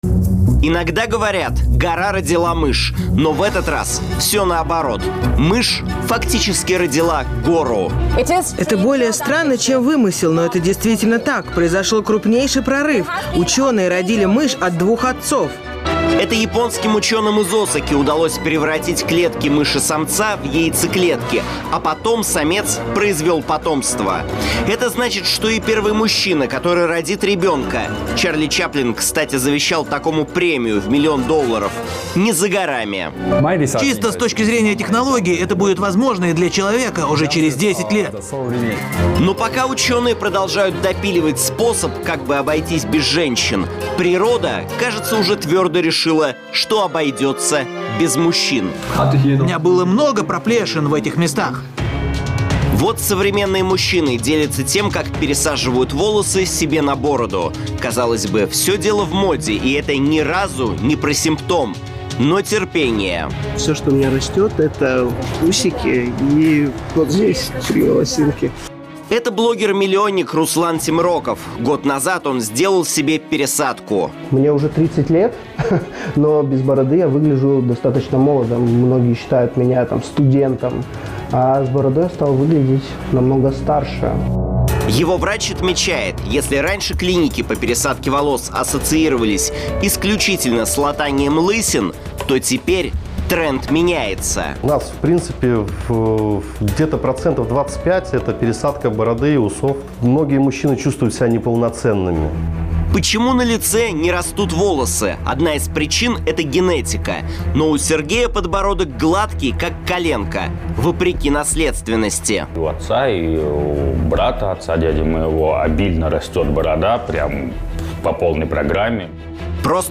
Источник: телеканал НТВ